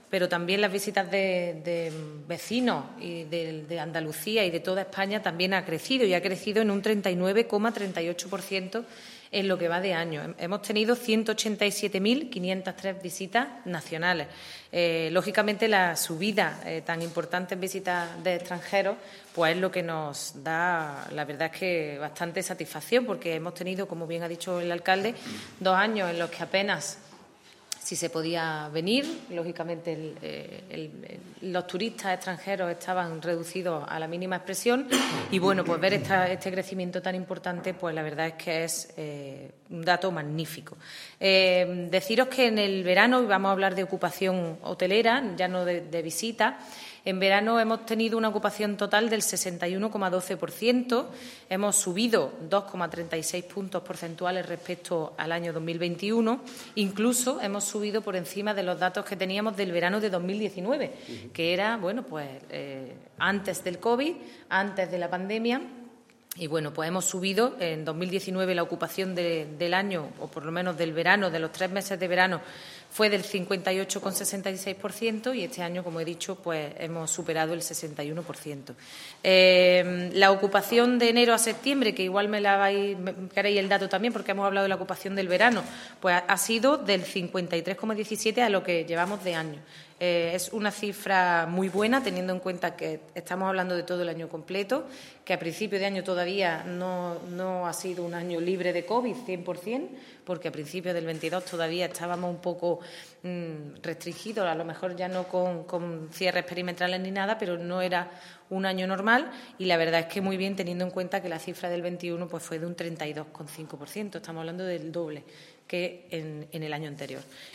El alcalde de Antequera, Manolo Barón, y la teniente de alcalde delegada de Turismo, Ana Cebrián, han informado hoy en rueda de prensa sobre los datos más relevantes que ha dado de sí el turismo en nuestra ciudad entre los meses de enero y septiembre del presente año 2022.
Cortes de voz